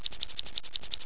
1 channel
spider.wav